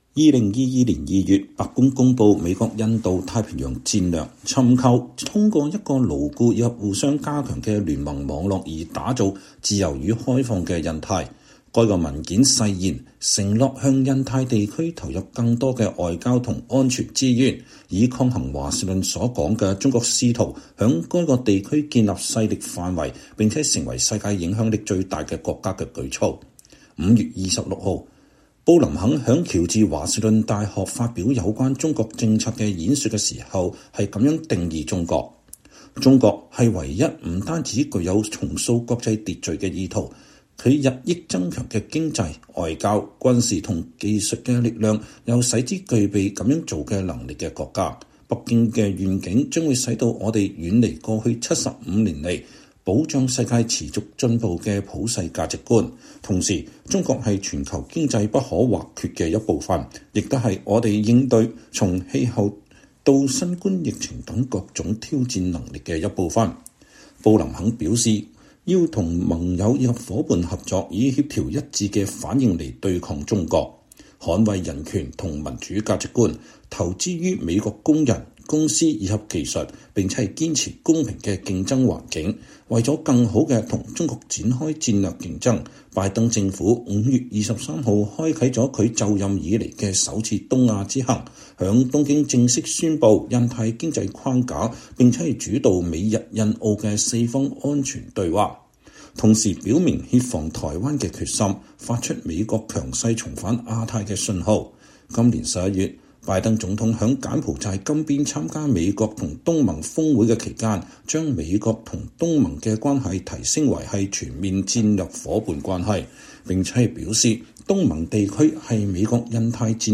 年終報導：拜習會晤使不斷下滑的關係穩定下來，美對華戰略的爭論繼續